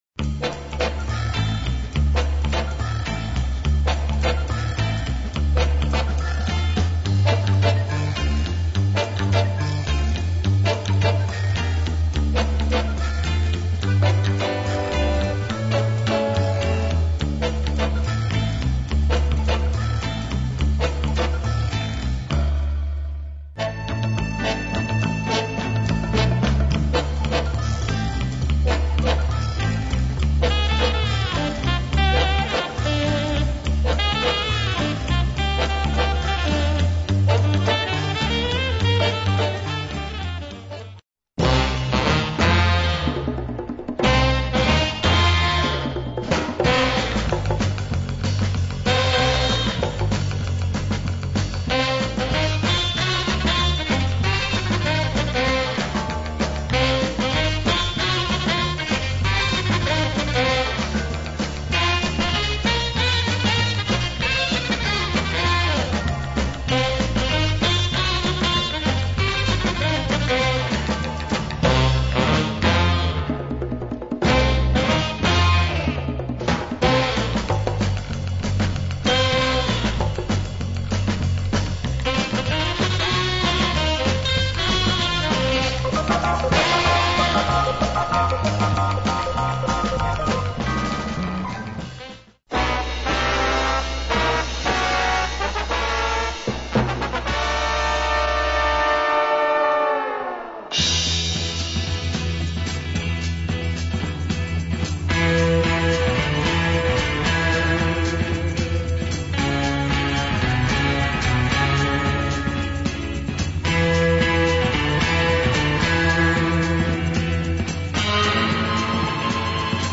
superb German cinematic jazz album from the 60's